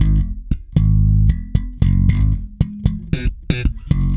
-JP THUMB.G#.wav